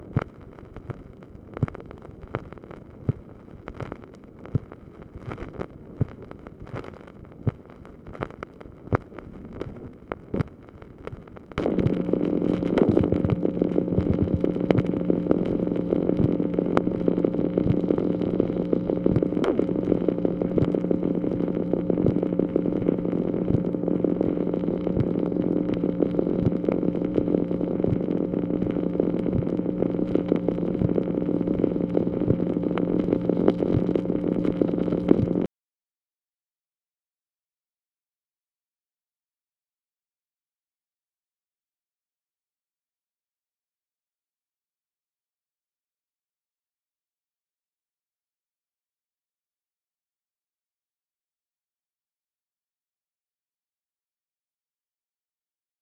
MACHINE NOISE, February 14, 1965
Secret White House Tapes | Lyndon B. Johnson Presidency